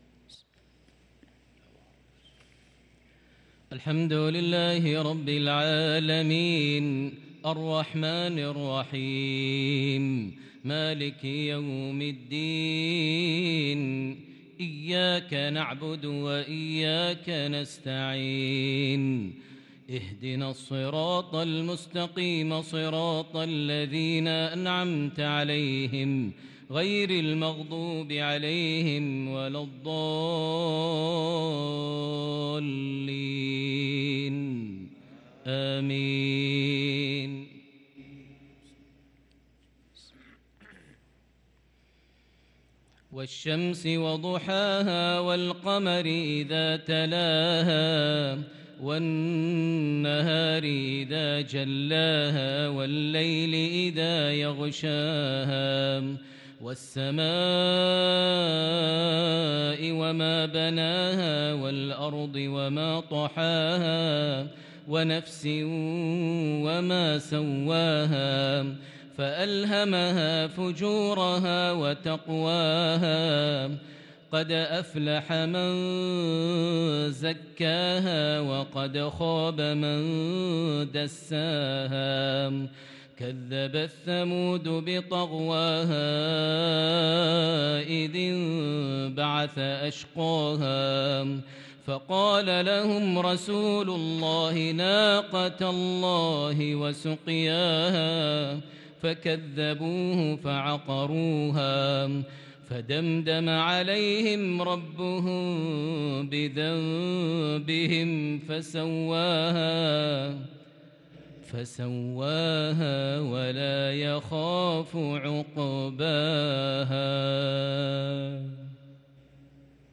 صلاة المغرب للقارئ ماهر المعيقلي 21 ربيع الأول 1444 هـ
تِلَاوَات الْحَرَمَيْن .